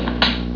slap.wav